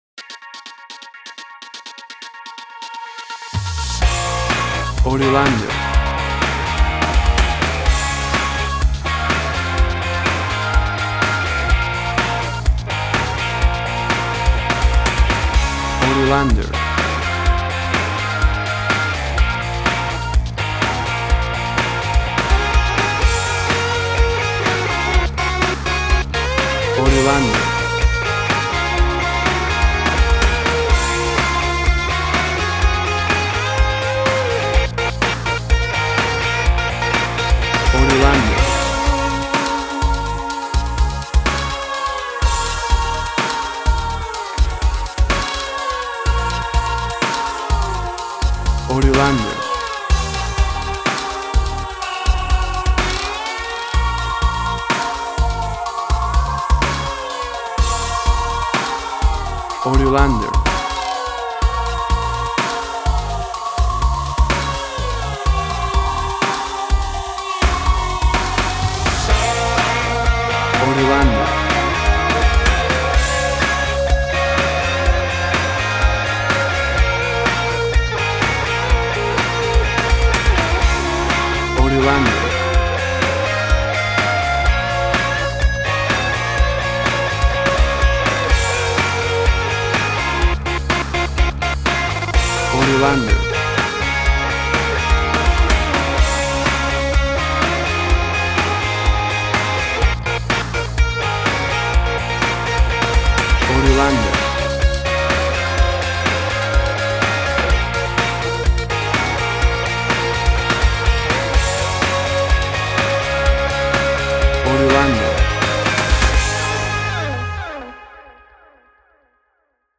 WAV Sample Rate: 24-Bit stereo, 44.1 kHz
Tempo (BPM): 125